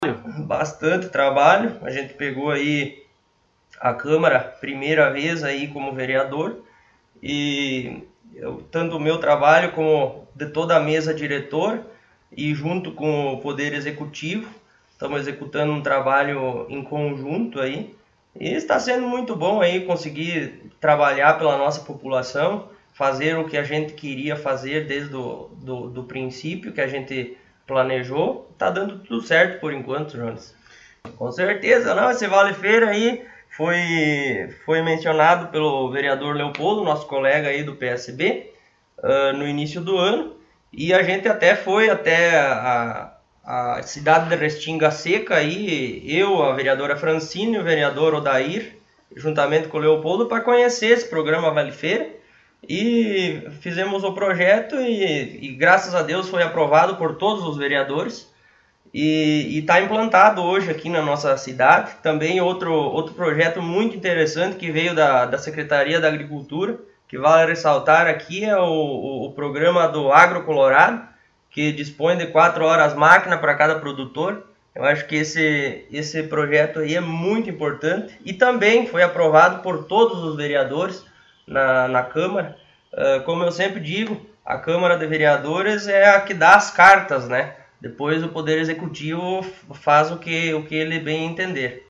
Presidente do Poder Legislativo, Taciano Paloschi, concedeu entrevista